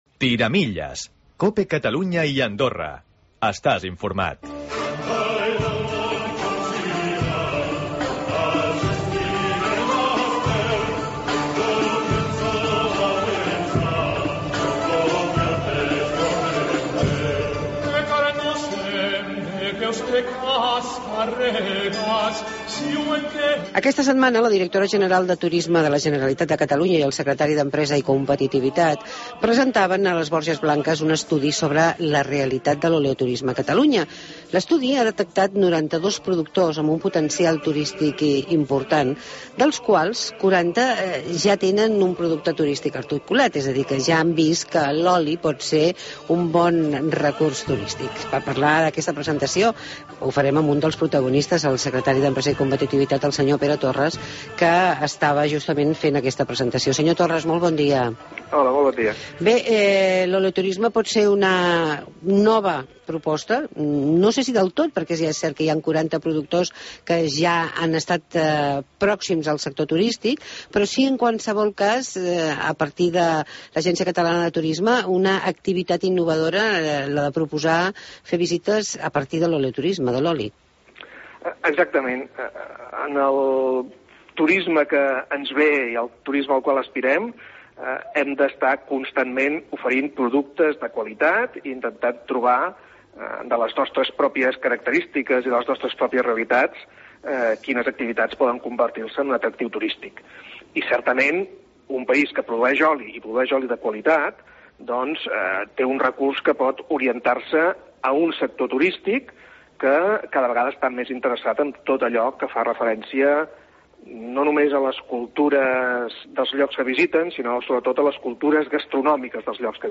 Amb Pere Torres, Secretari d'Empresa i Competivitat, parlem de L'Oleoturisme a Catalunya.